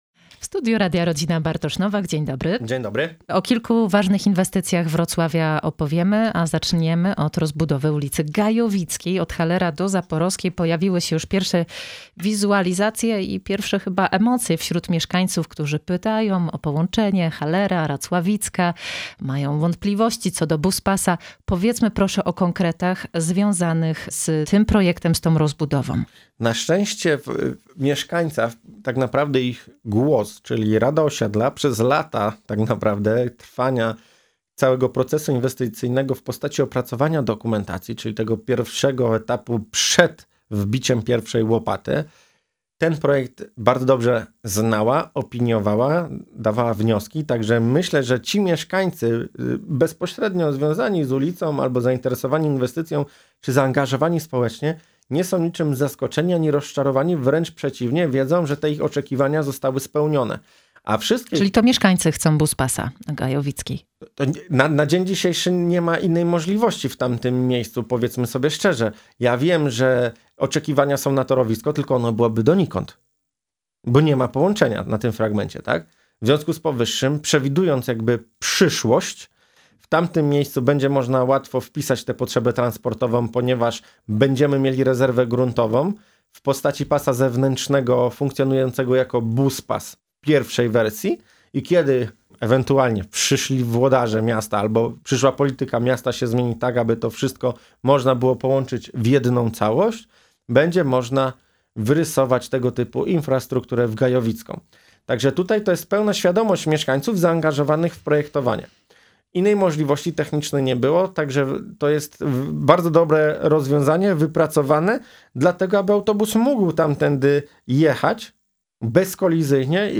Więcej o tym w rozmowie